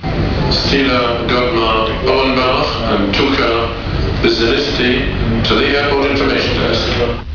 Announcers were tricked into saying them under the pretence that they were foreign names.
We put the tape machine in our bag with the microphone poking out of the top.
This is the reason the last one sounds so crap 'cos Gatwick is a much noisier place and the ceilings are high, and it was difficult to get near a speaker.